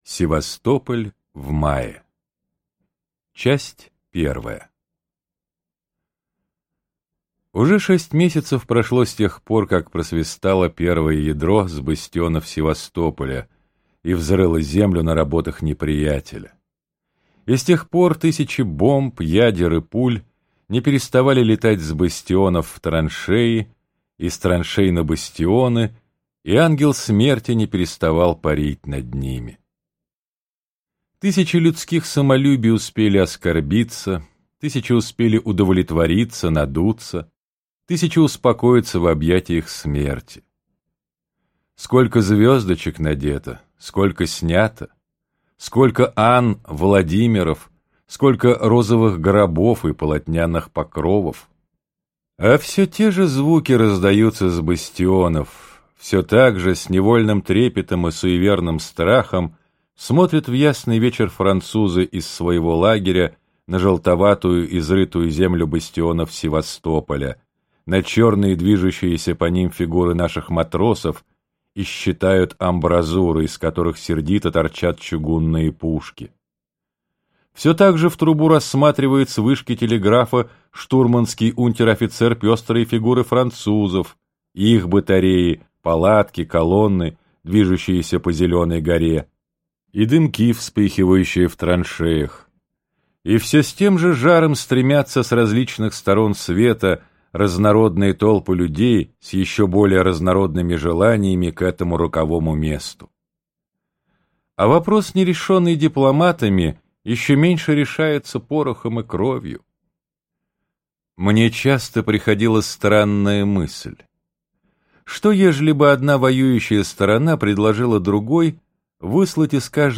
Севастопольские рассказы - аудио рассказ Толстого Льва Николаевича.